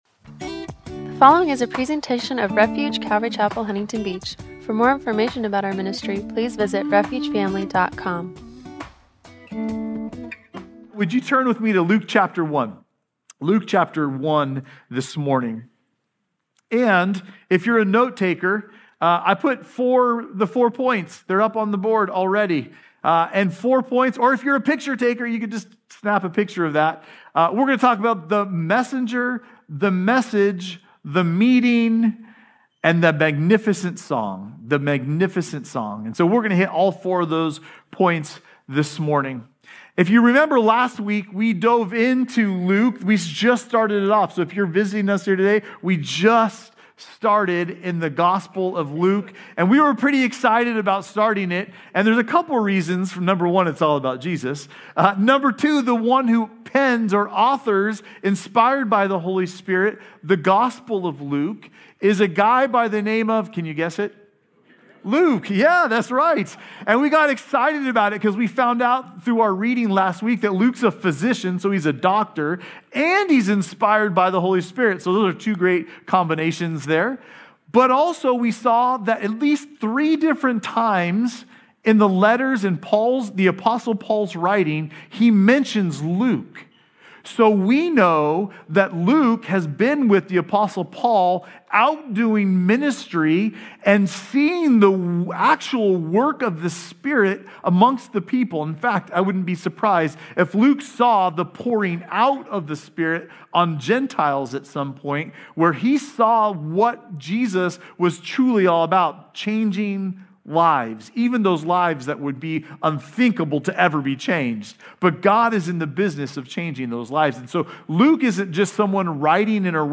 “The Birth Announcement”-Luke 1:26-56 – Audio-only Sermon Archive
Service Type: Sunday Morning